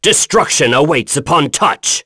Lusikiel-Vox_Skill5.wav